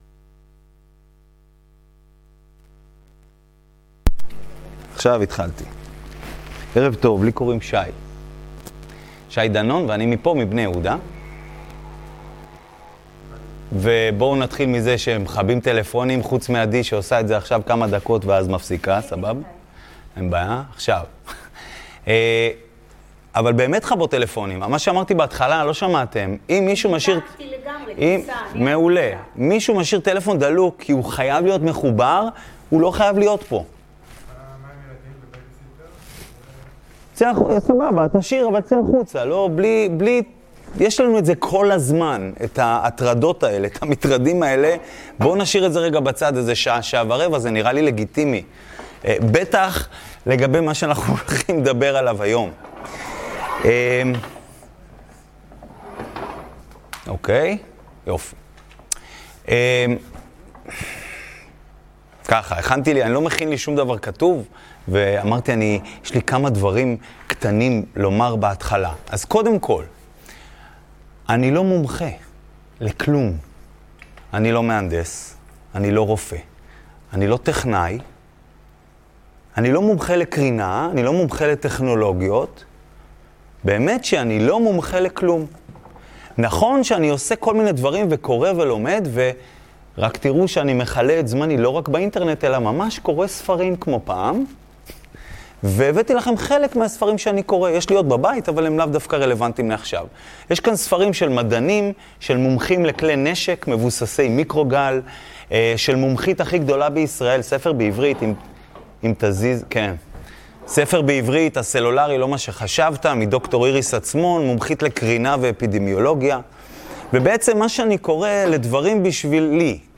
הרצאה על דור 5. גבעת יואב.